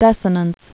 (dĕsə-nəns)